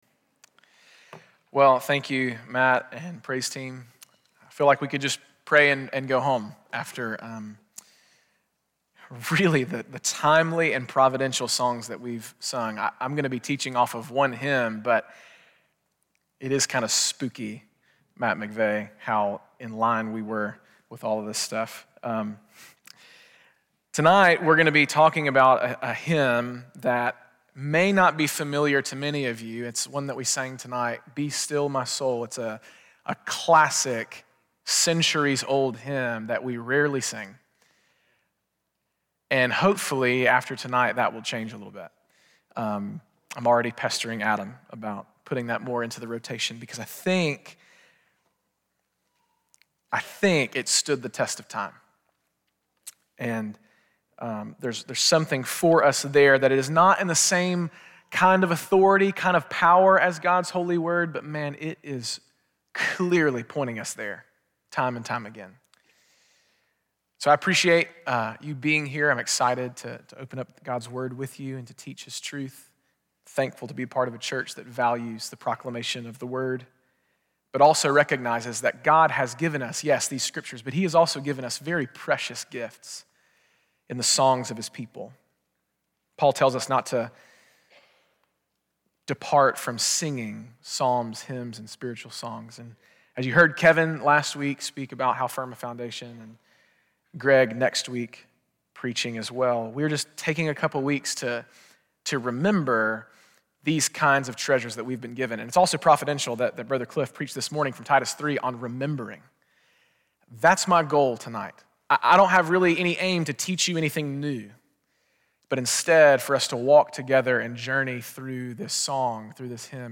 Great Hymns Of The Faith Service Type: Sunday Evening Be still